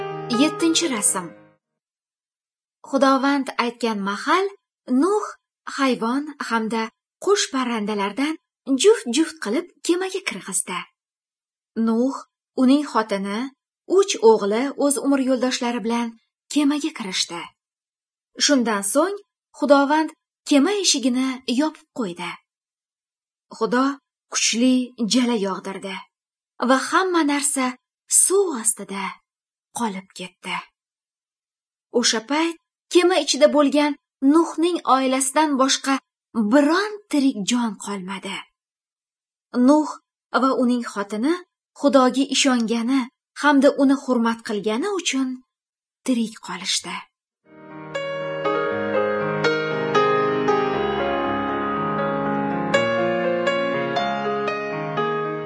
Good News (Female)